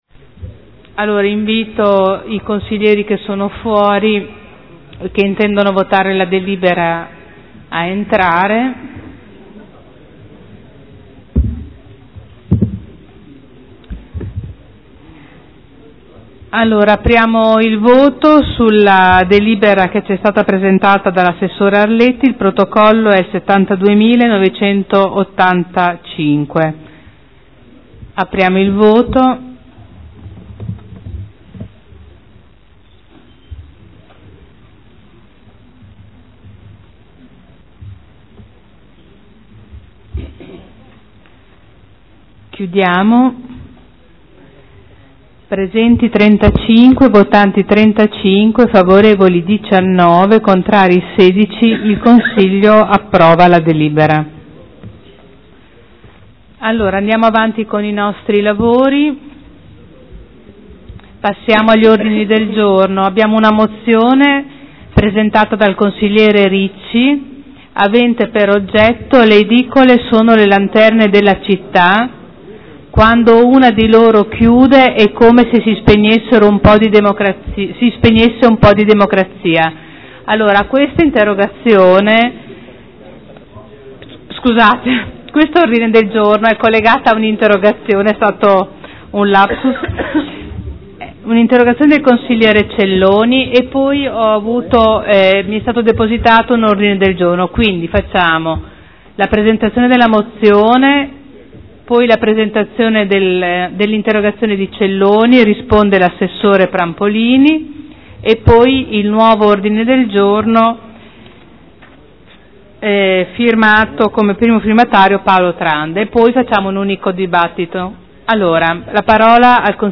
Presidente — Sito Audio Consiglio Comunale
Seduta del 24/06/2013. Mette ai voti. Delibera: Tributo comunale sui rifiuti e sui servizi indivisibili – TARES – Approvazione delle tariffe, del Piano Economico Finanziario, del Piano annuale delle attività per l’espletamento dei servizi di gestione dei rifiuti urbani e assimilati.